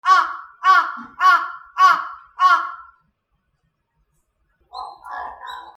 カラス つがい
/ D｜動物 / D-05 ｜鳥 / 20｜カラス
なきごえ 『アー』